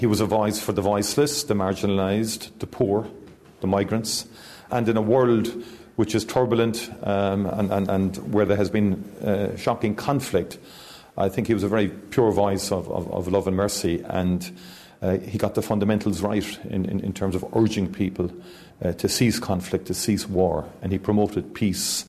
Taoiseach Micheál Martin says the world has lost an exceptional leader